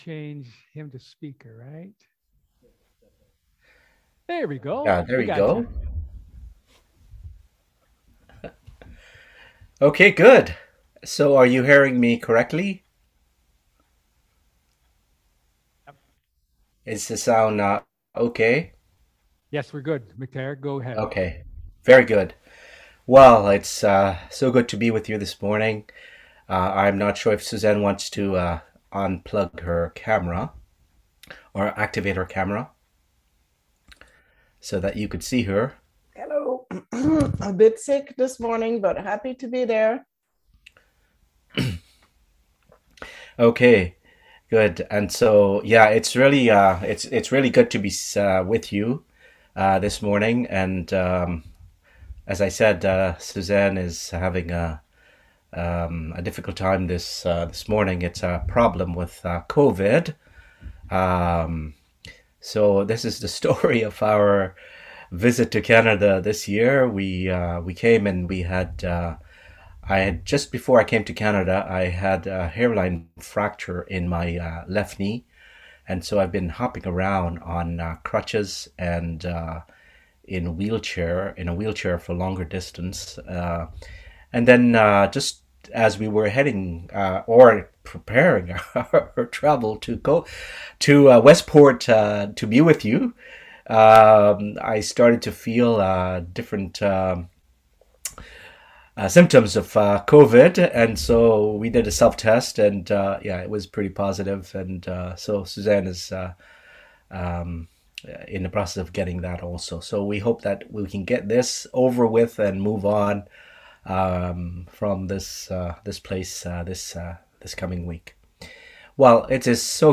Ecclesiastes 11:7-12:14 Service Type: Sermon